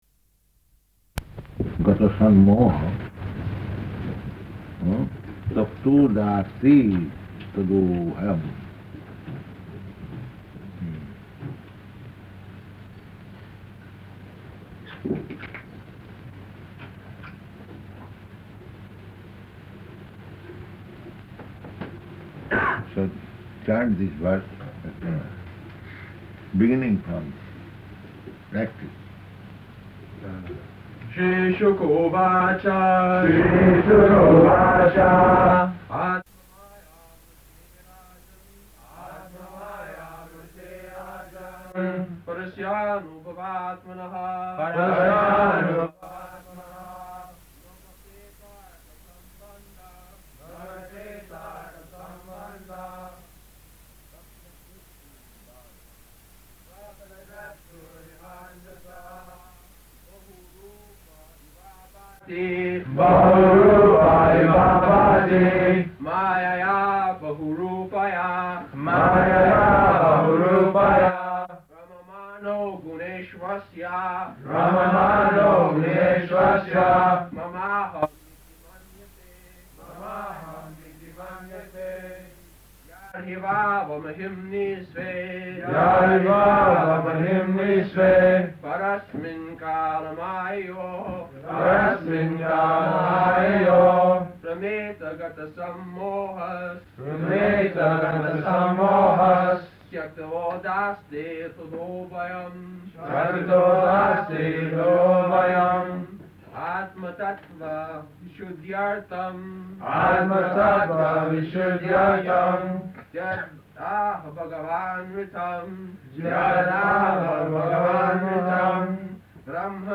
Śrīmad-Bhāgavatam 2.9.1–4 --:-- --:-- Type: Srimad-Bhagavatam Dated: April 24th 1972 Location: Tokyo Audio file: 720424SB.TOK.mp3 Prabhupāda: ...gata-sammohas tyaktvodāste tadobhayam.
[devotees continue repeating last verse, with Prabhupāda correcting]